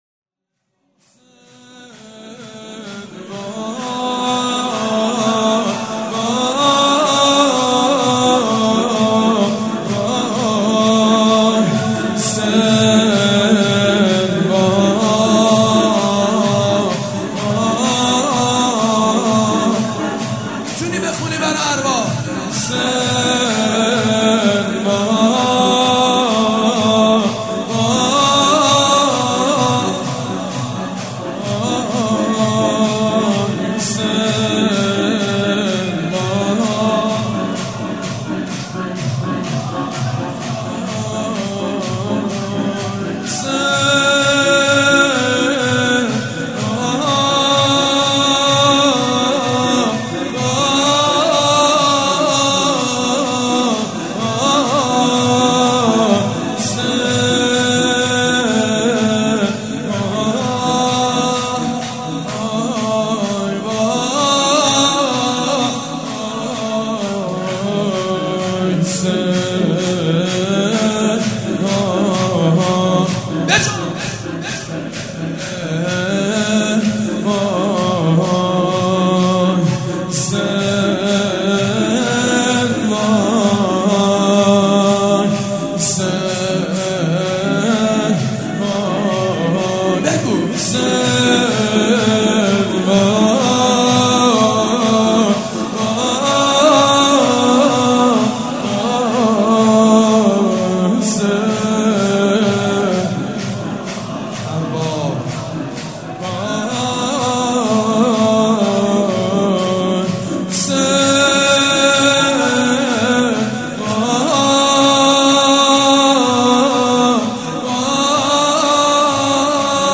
مراسم شهادت حضرت زینب کبری(سلام الله عليها)
شور
مداح
حاج سید مجید بنی فاطمه
شور اول.mp3